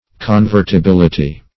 Convertibility \Con*vert`i*bil"i*ty\, n.